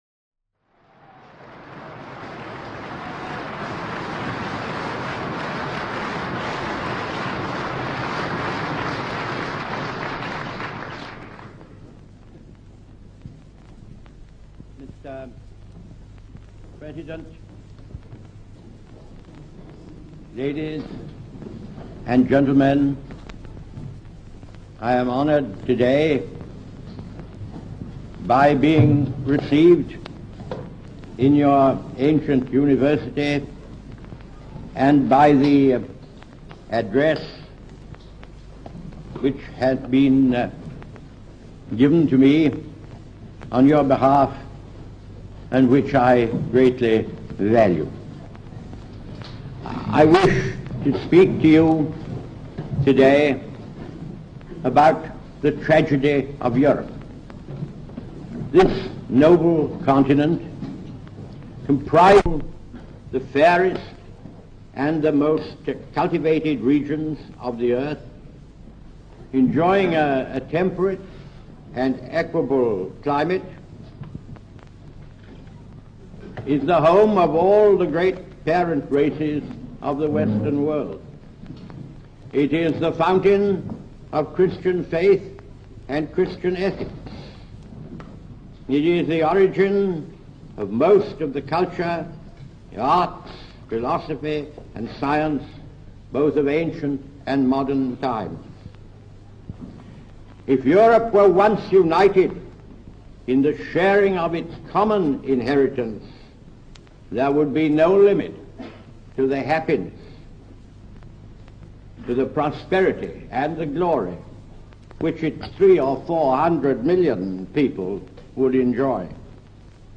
churchillswitzerland.mp3